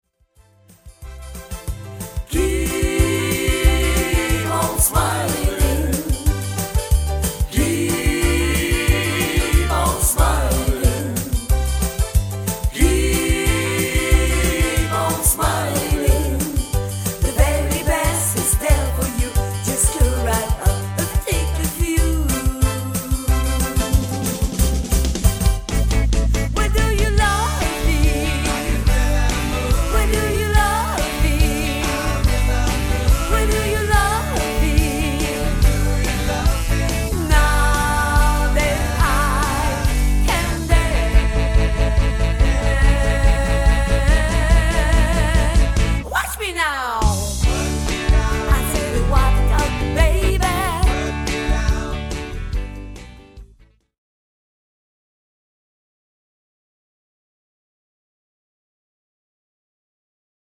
chanteuse & choriste